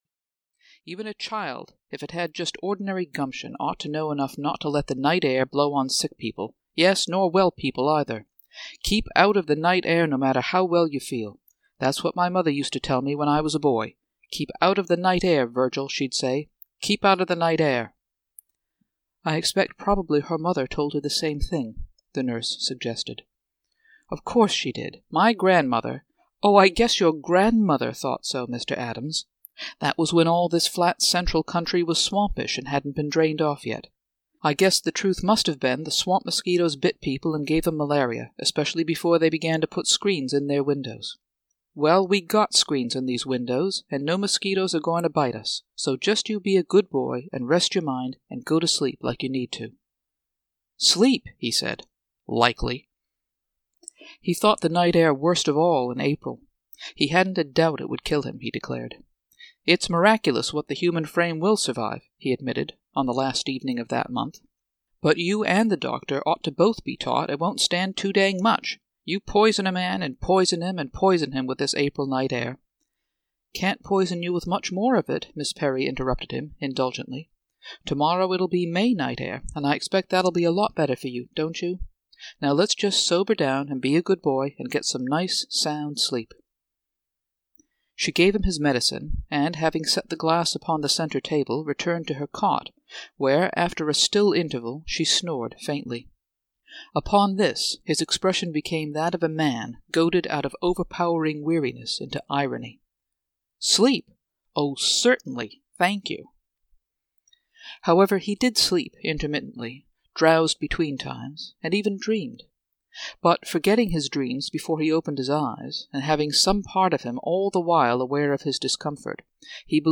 Alice Adams (EN) audiokniha
Ukázka z knihy